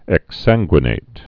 (ĕks-sănggwə-nāt)